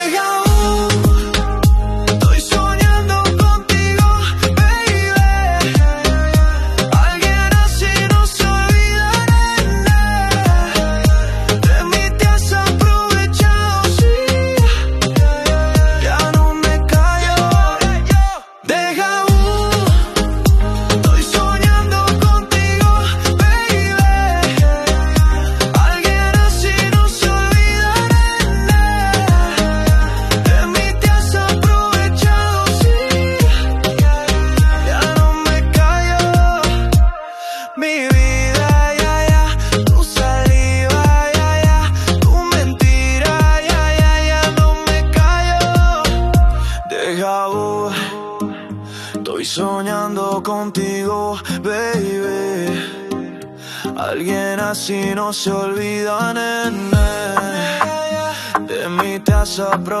Un mix di ritmo e verità.